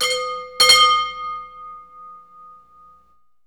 Index of /90_sSampleCDs/E-MU Producer Series Vol. 3 – Hollywood Sound Effects/Ambient Sounds/Gas Station
GAS STATI00R.wav